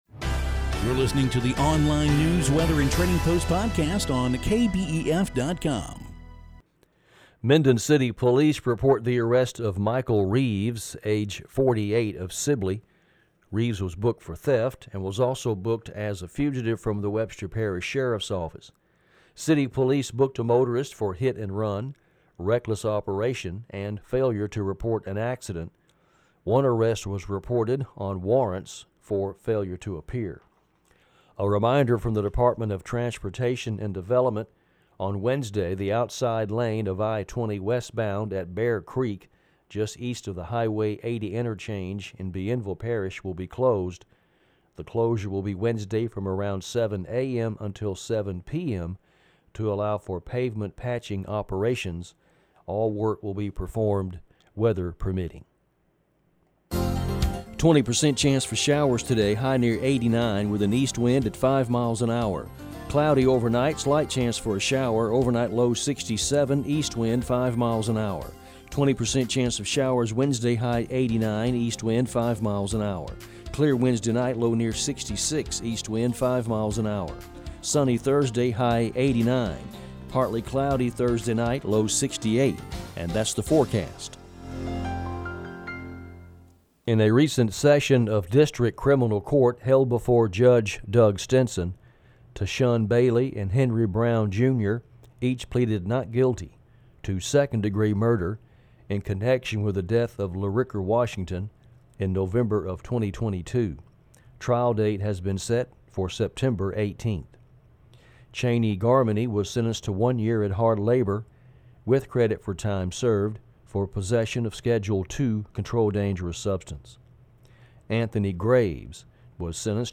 Daily news, weather and trading post podcast.